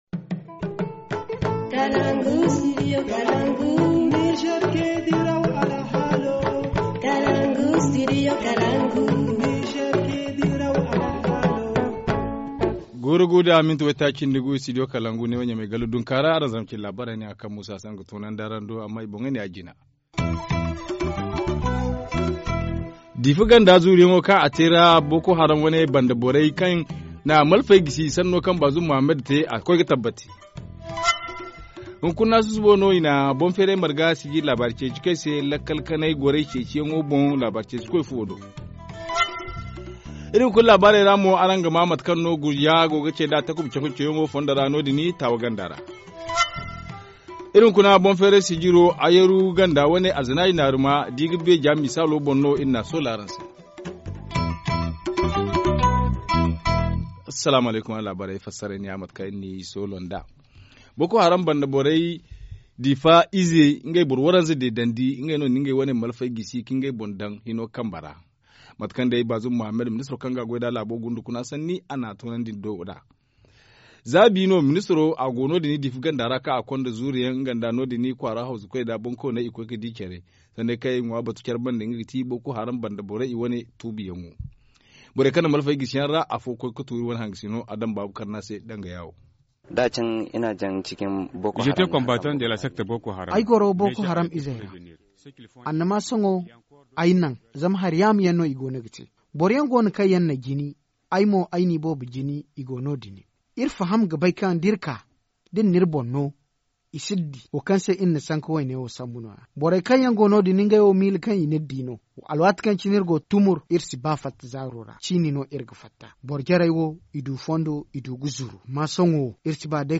Journal du 28 décembre 2016 - Studio Kalangou - Au rythme du Niger